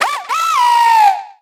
Cri d'Efflèche dans Pokémon Soleil et Lune.